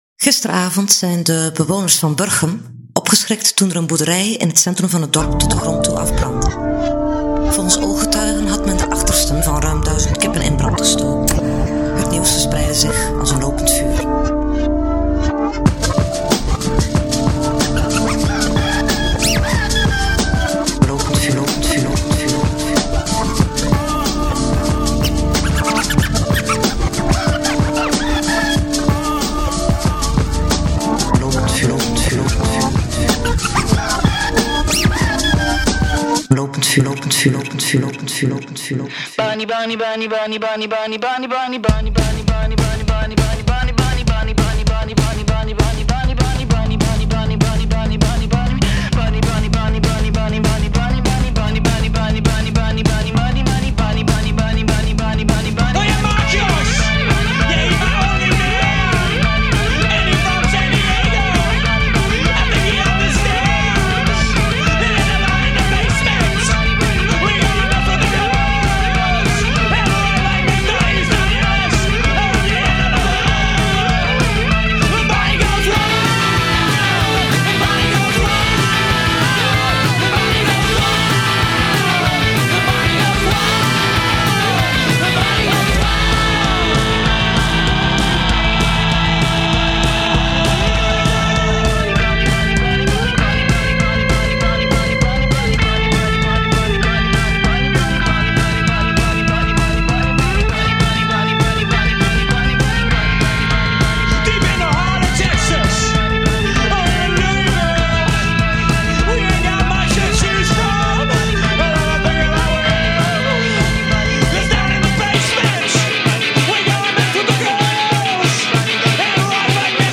Onze eerste lustrum-episode wordt gesierd door een interview